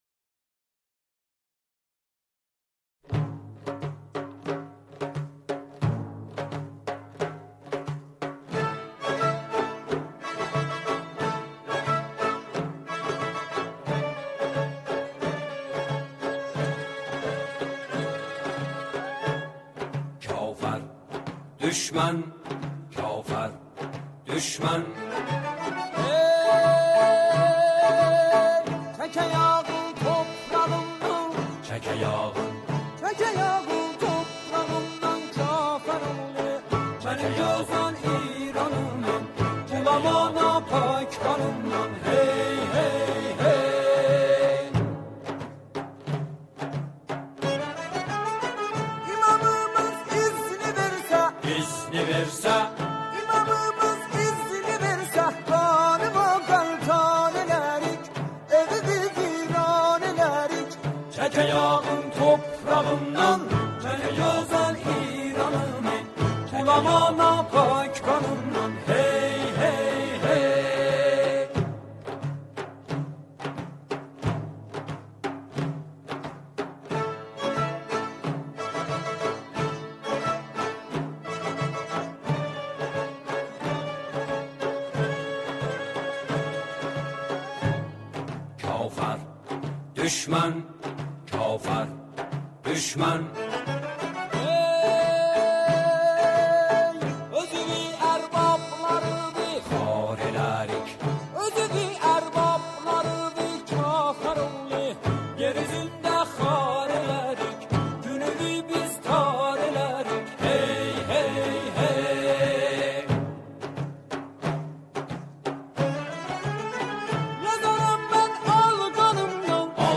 این سرود با گویش آذری اجرا شده است.